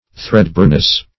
Threadbareness \Thread"bare`ness\, n. The state of being threadbare.